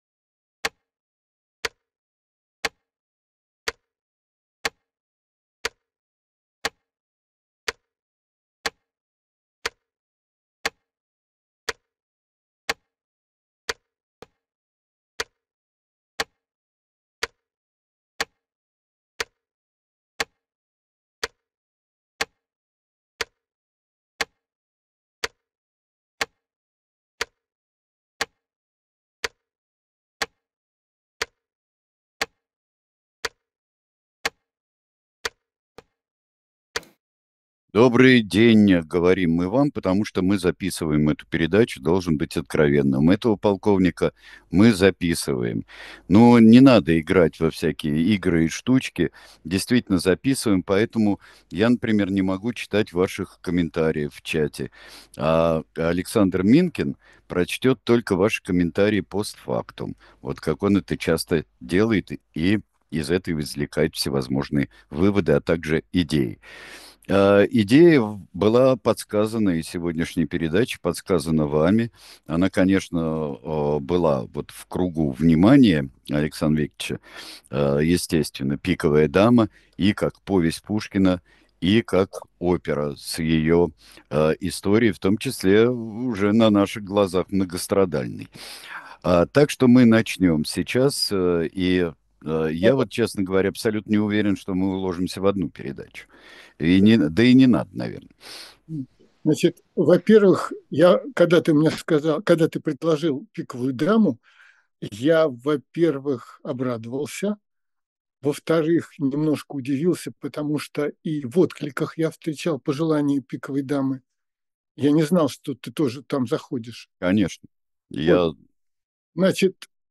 Эфир ведут Александр Минкин и Сергей Бунтман